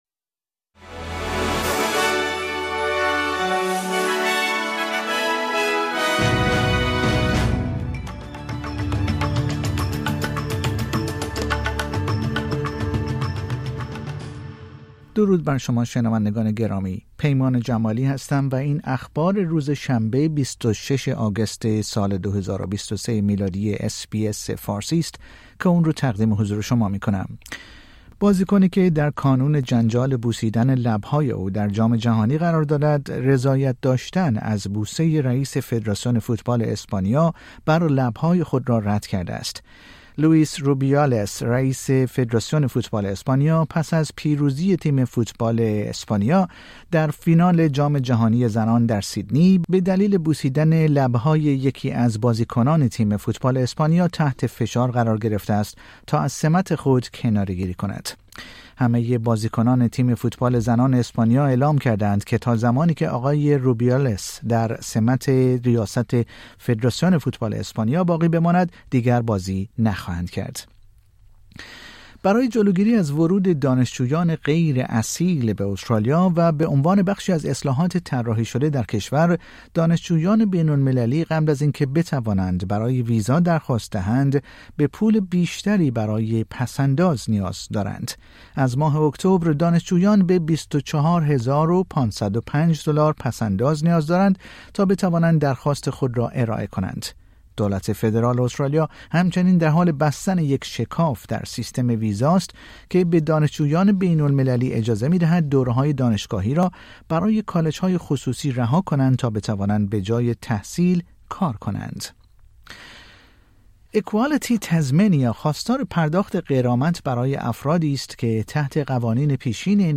پادکست خبری آخر هفته اس بی اس فارسی